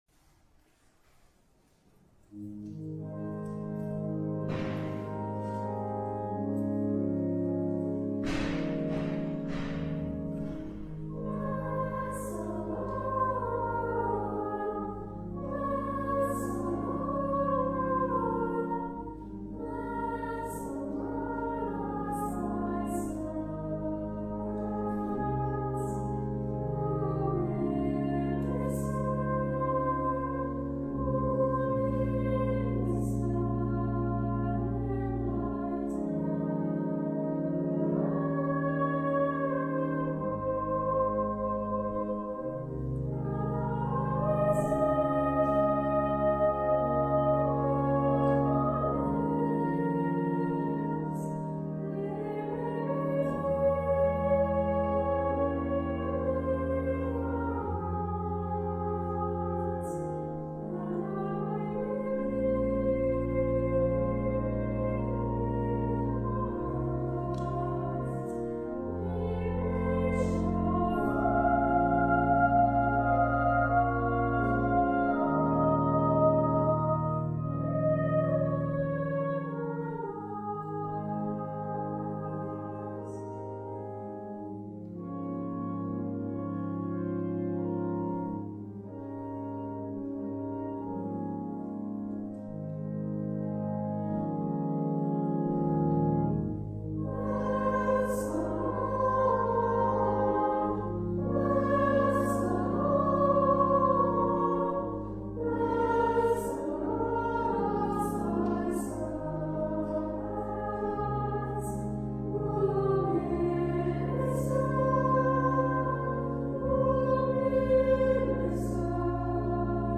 Voicing: "Upper voices"